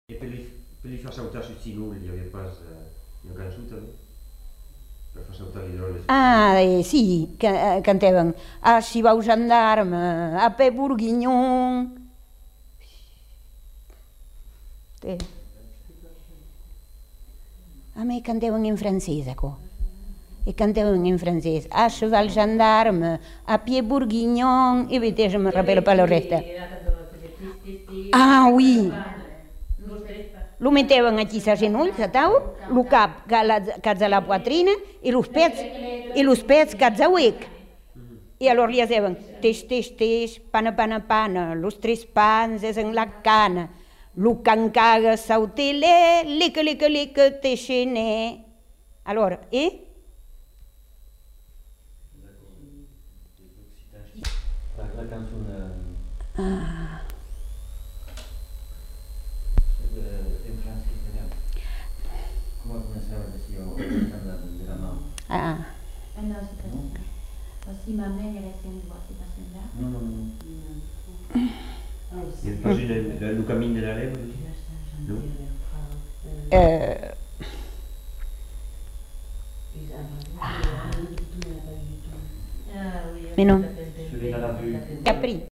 Aire culturelle : Marmandais gascon
Lieu : Tonneins
Effectif : 1
Type de voix : voix de femme
Production du son : chanté
Classification : formulette enfantine